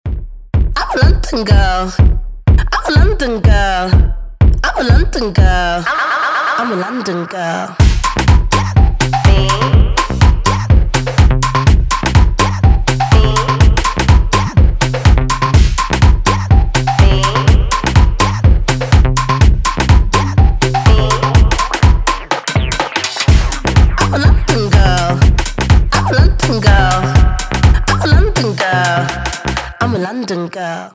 веселые
EDM
house
озорные